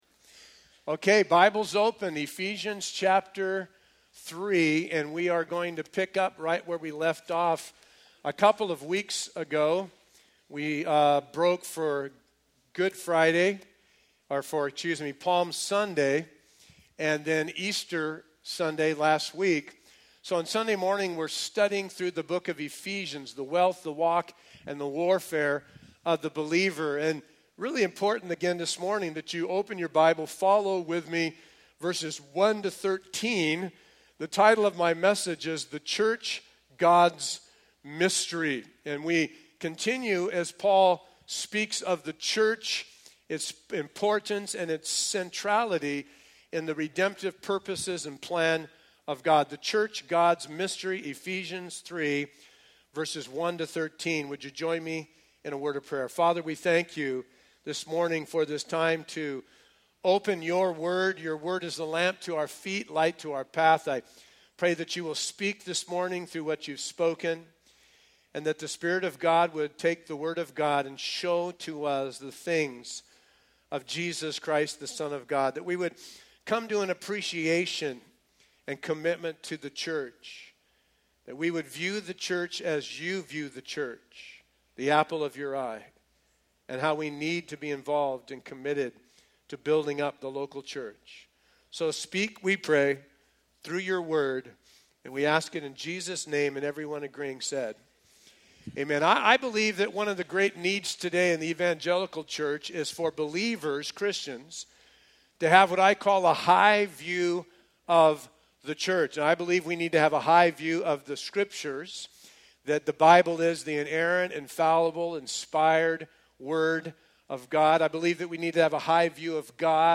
A verse-by-verse sermon through Ephesians 3:1-13